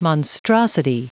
Prononciation du mot monstrosity en anglais (fichier audio)
Prononciation du mot : monstrosity
monstrosity.wav